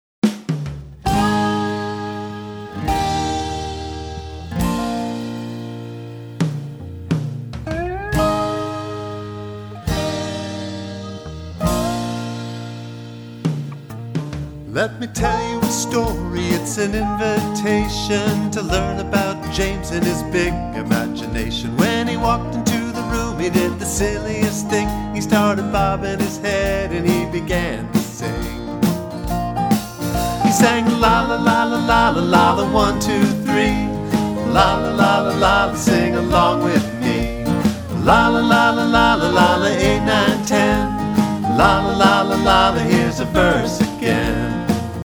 With a youthful, warm and energetic style